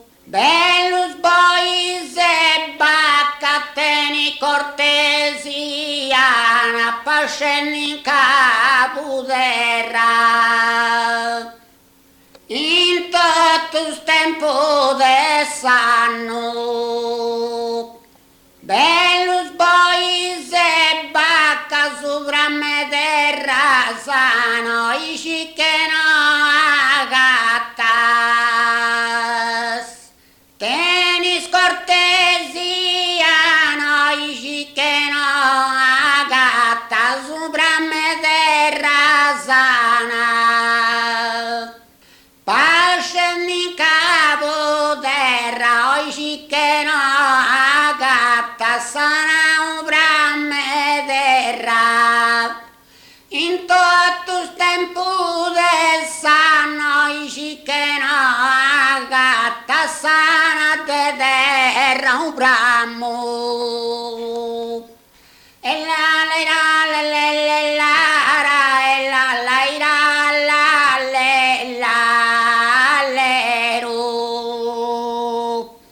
muttetu